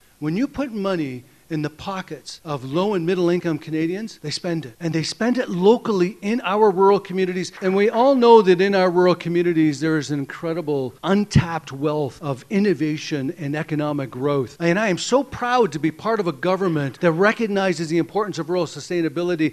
That was his main message as he kicked off his campaign towards the 2019 federal election, with Prime Minister Justin Trudeau on hand, at the Strathcona Paper Centre in Napanee.
An enthusiastic crowd listened in as Bossio outlined his plan to continue to push for a strong middle class and to campaign hard for rural Canadians.